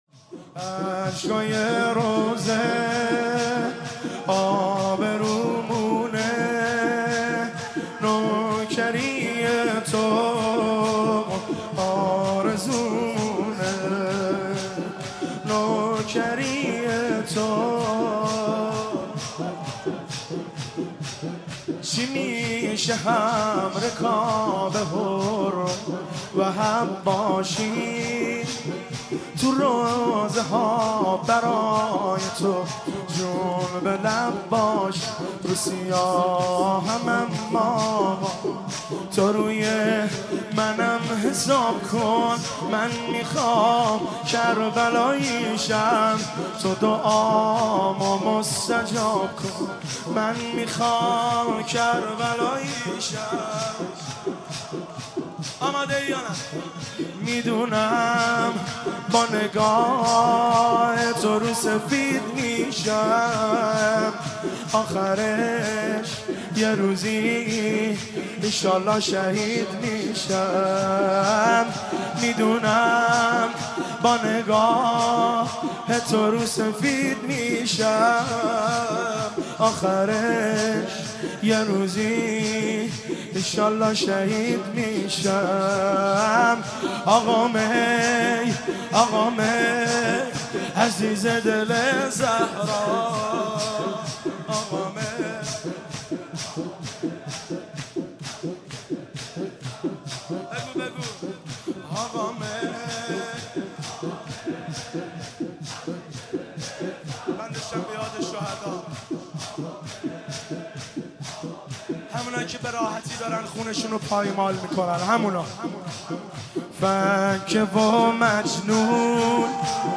مداحی اشهکای روضه، آبرومونه(شور)
شب تاسوعا محرم 1392
هیئت خادم الرضا(ع) قم